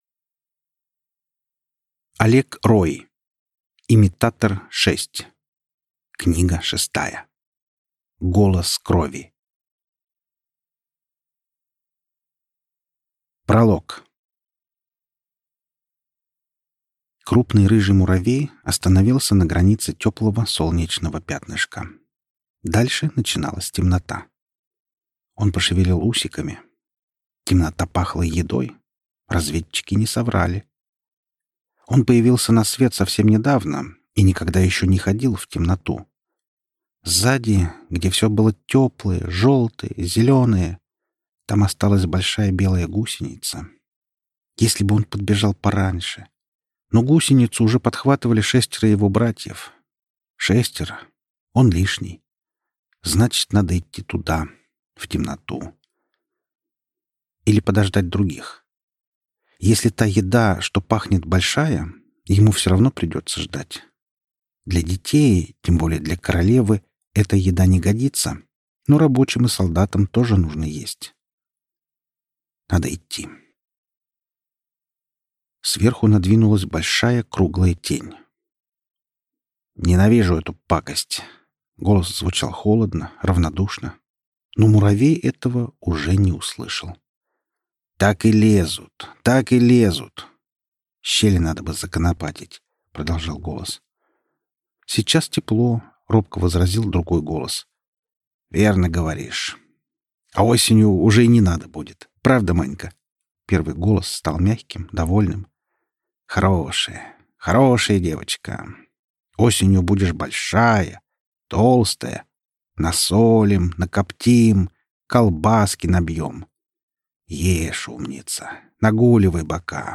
Аудиокнига Имитатор. Книга шестая. Голос крови | Библиотека аудиокниг